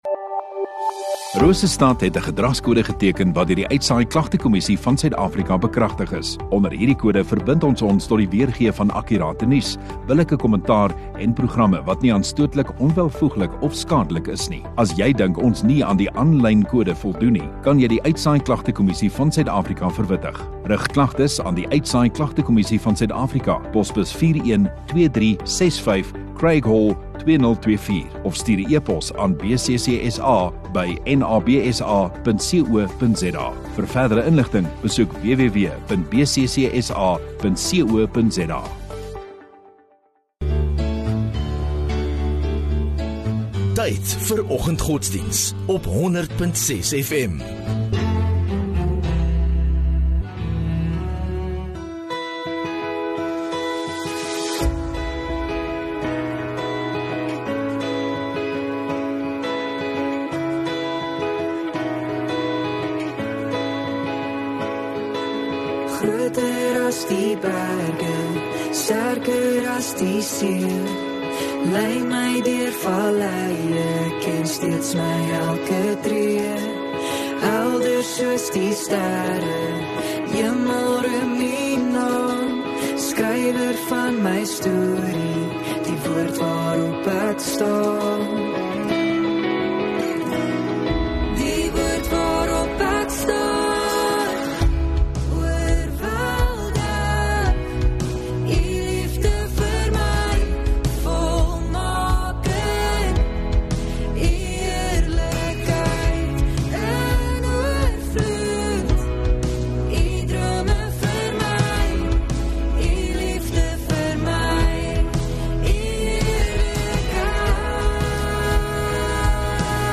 6 Feb Donderdag Oggenddiens